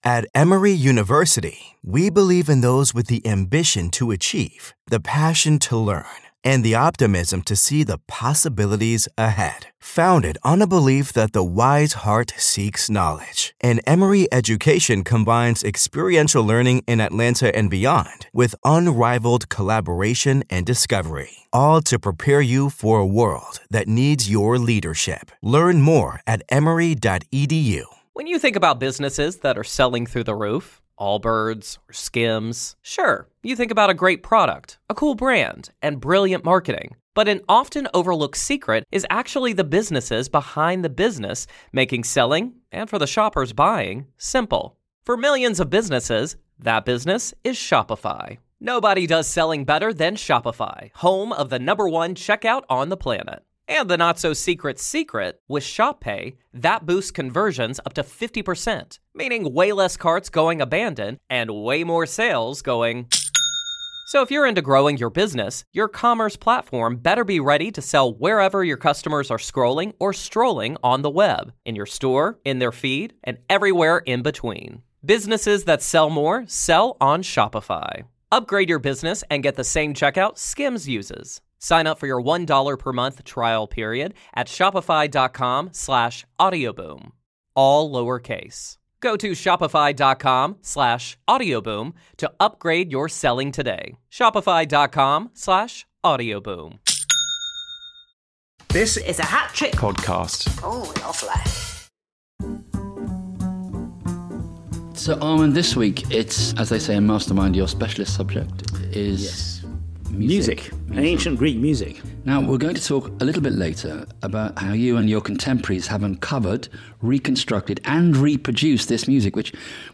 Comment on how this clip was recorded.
‘Euripides Orestes’ performed by a mixed choir